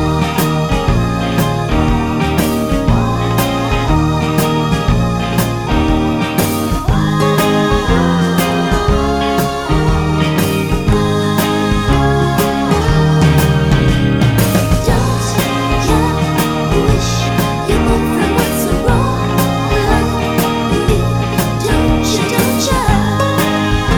for solo male Rock 'n' Roll 3:53 Buy £1.50